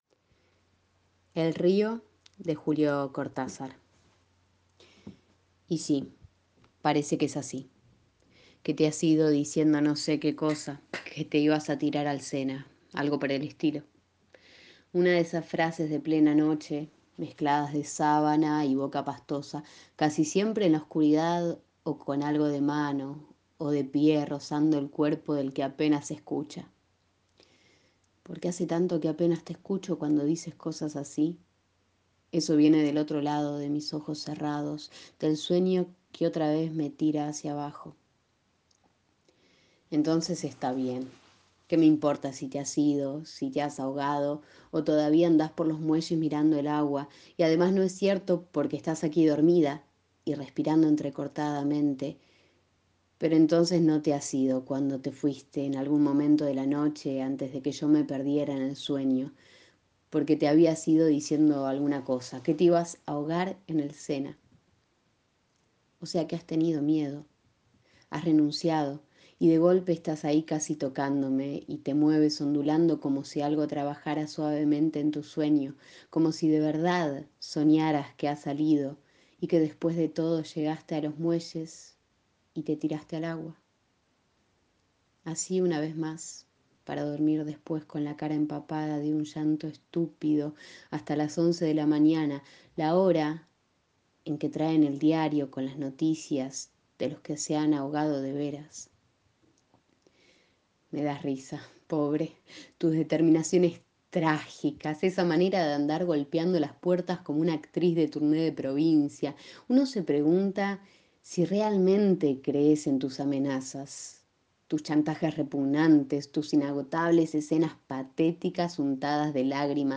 Lo lee desde Francia con el tono de Argentina sabiendo que la voz no tiene patria ni frontera.